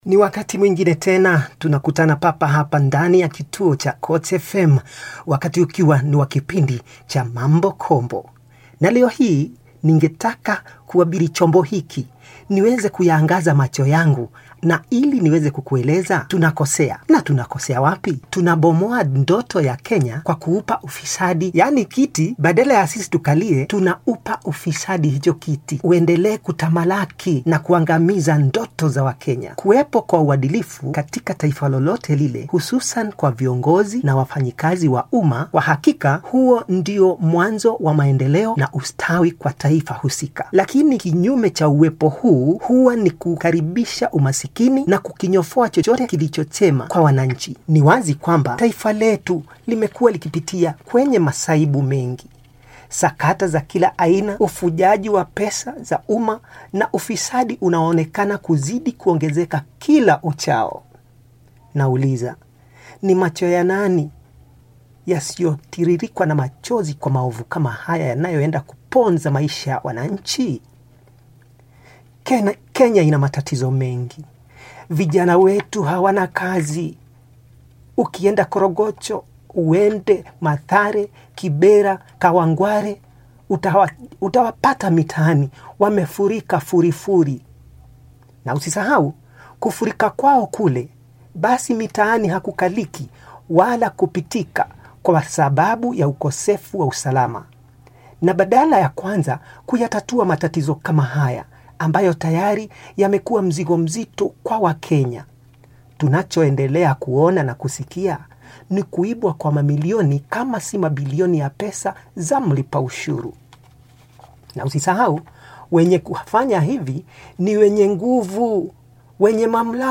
Koch FM talk show on corruption